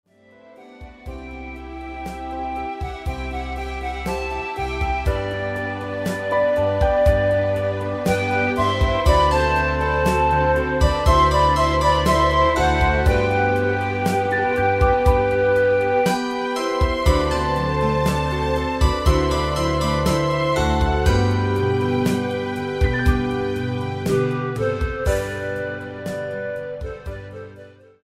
mit Panflötenstimme